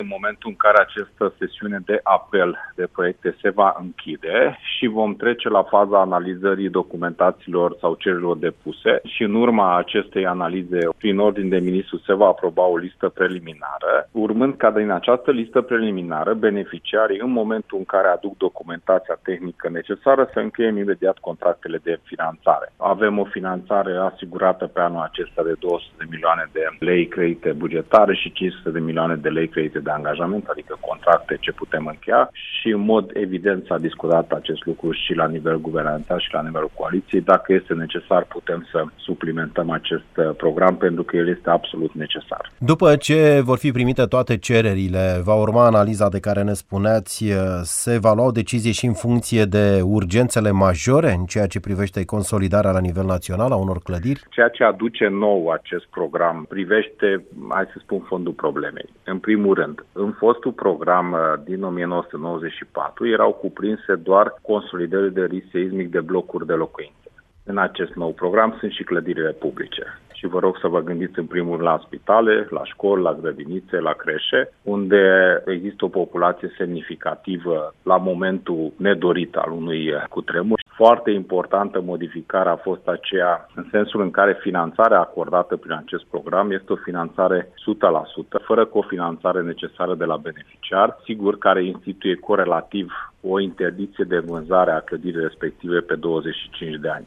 Ministrul Dezvoltării Regionale, Cseke Attila într-un interviu